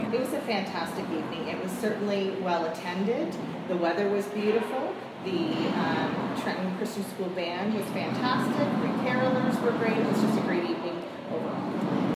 Children sang classic Christmas carols as the group made its way to Fraser Park.
selection-from-jingle-bell-walk-8-2.mp3